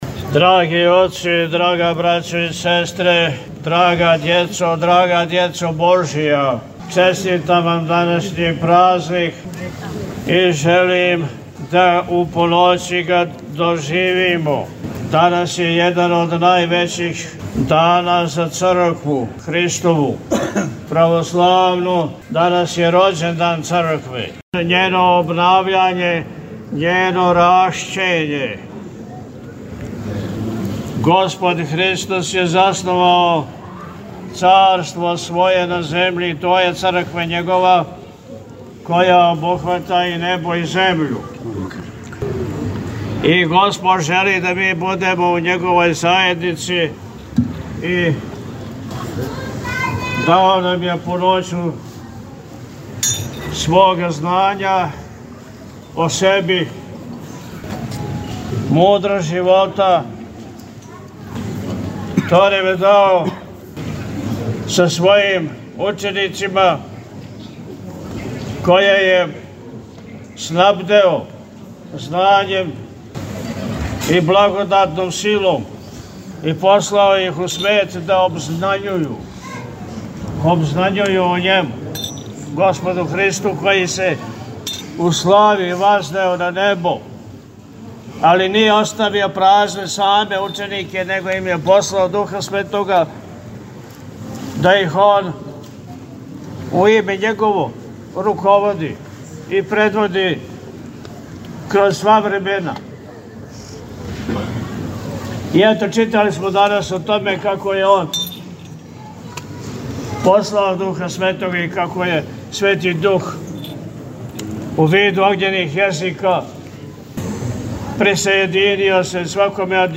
Besjeda-Pljevlja-Trojice.mp3